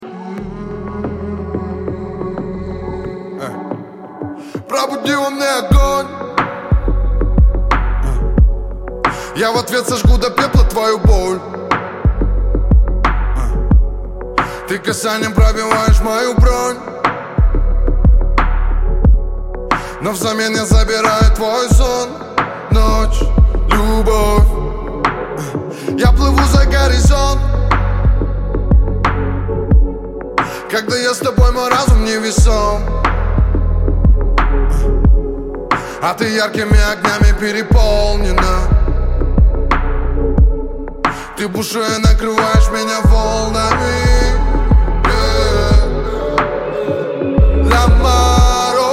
• Качество: 128, Stereo
лирика
Хип-хоп
спокойные
романтичные
христианский рэп